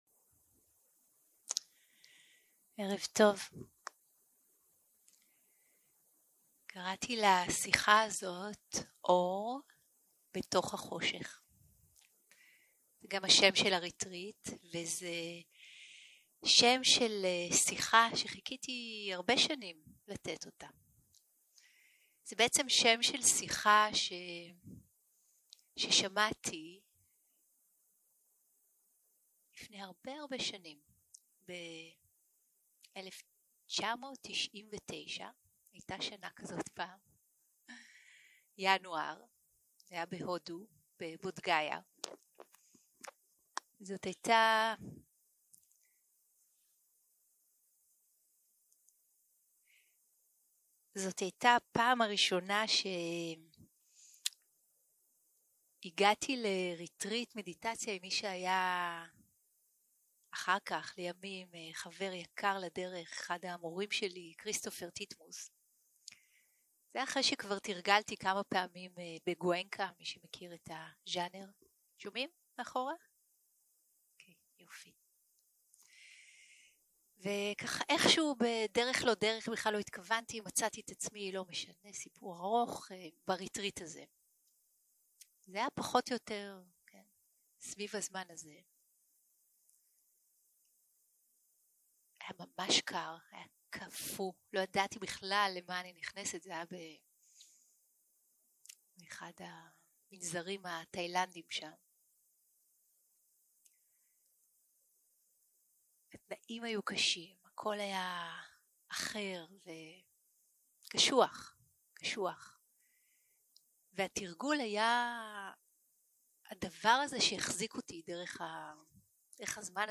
יום 2 - הקלטה 5 - ערב - שיחת דהרמה - אור בתוך החושך
Dharma type: Dharma Talks